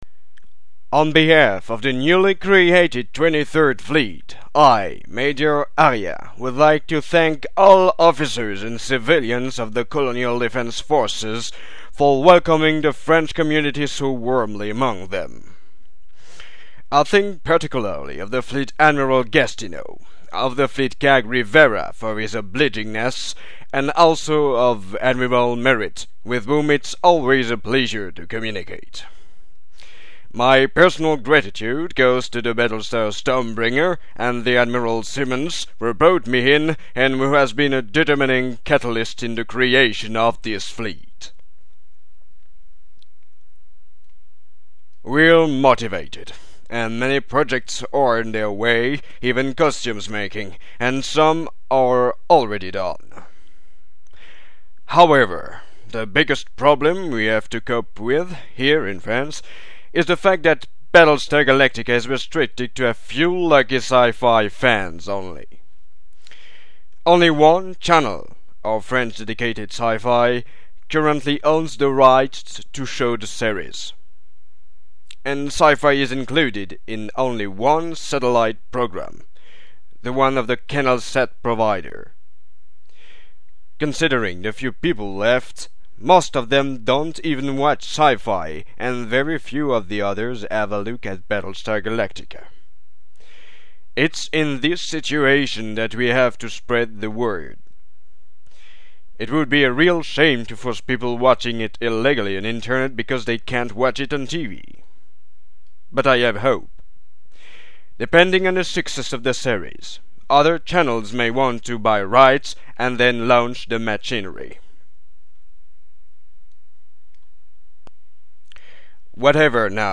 intro_23_speech.mp3